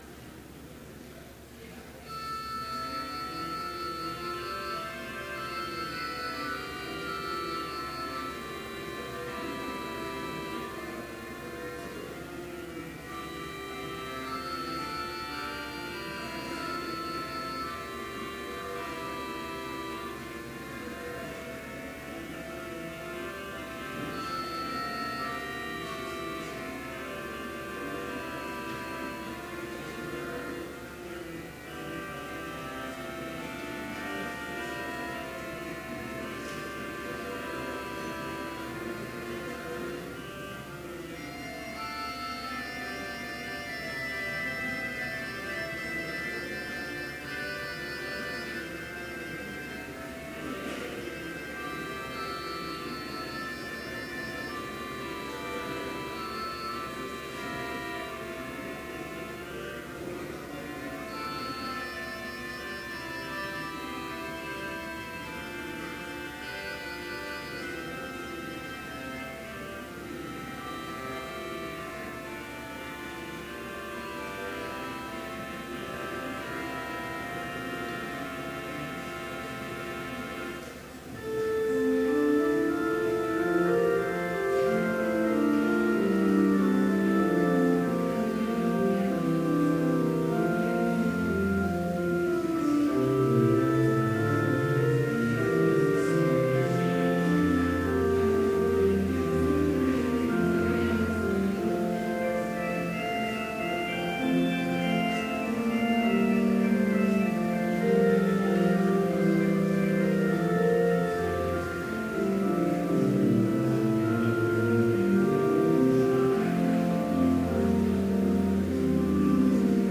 Complete service audio for Chapel - April 12, 2018